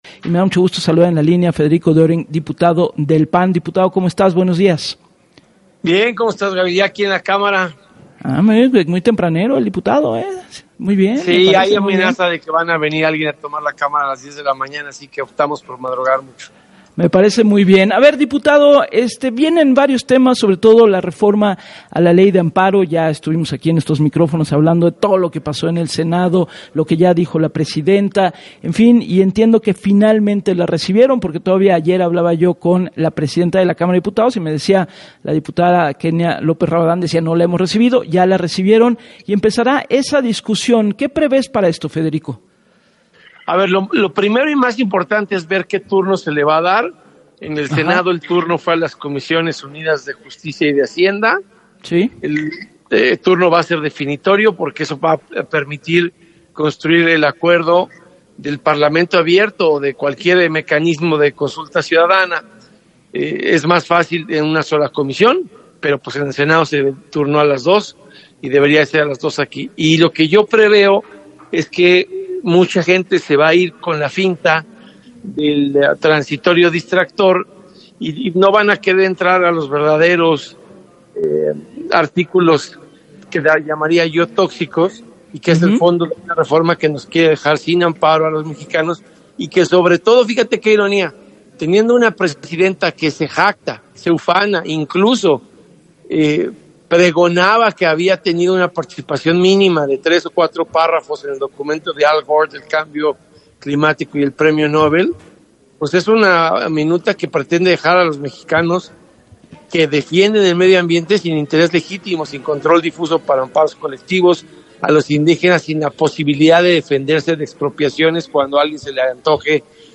El diputado puntualizó, en entrevista con Gabriela Warkentin, para “Así las Cosas”, que “mucha gente se va con el transitorio distractor y no van a querer entrar a los verdaderos artículos tóxicos, y que son el fondo de esta reforma que nos quiere dejar sin amparo a los mexicanos”.